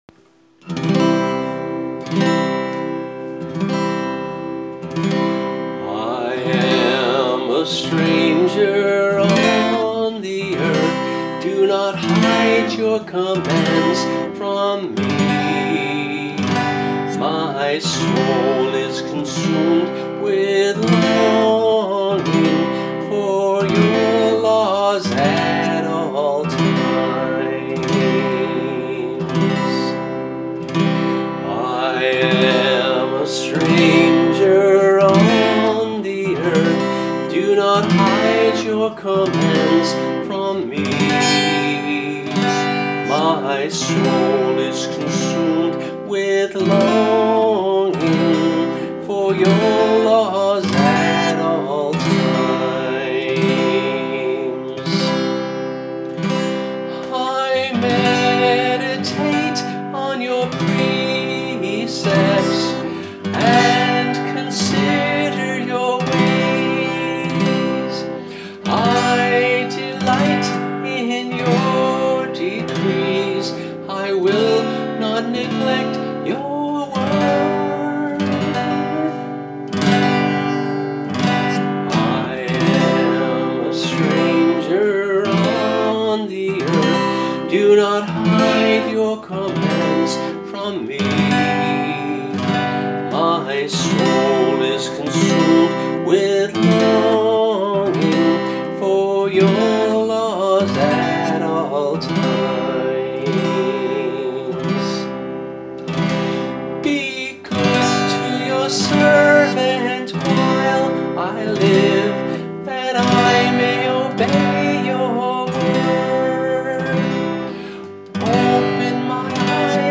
[MP3 - voice & guitar]